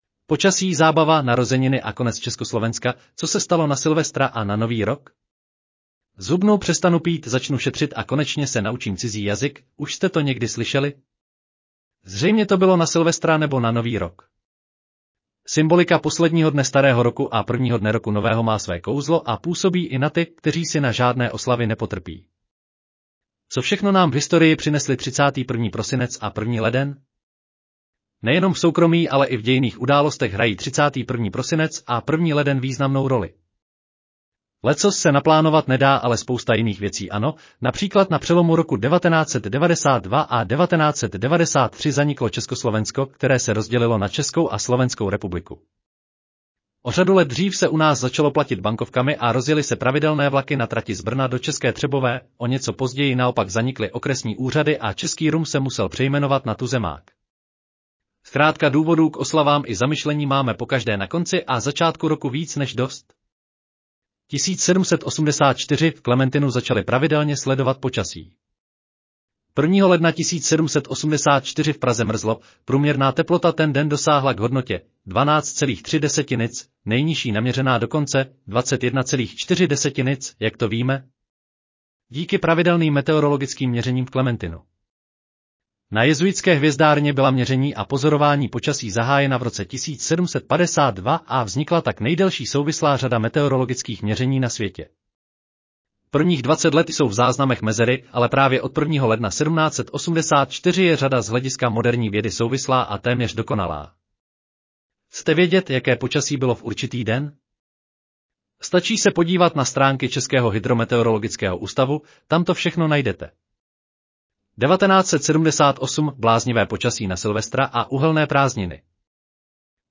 Audio verze článku Počasí, zábava, narozeniny a konec Československa: co se stalo na Silvestra a na Nový rok?